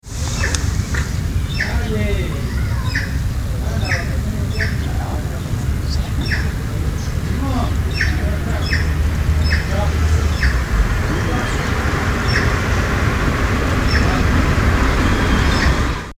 Nombre en español: Urraca Común
Nombre en inglés: Plush-crested Jay
Fase de la vida: Adulto
Localidad o área protegida: Castelar
Condición: Silvestre
Certeza: Observada, Vocalización Grabada